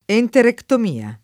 enterectomia [ enterektom & a ]